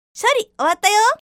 Windowsシステム音声集
Windowsの起動音や警告音を中心に、パソコンの効果音として使える音声のセットです(全25個)。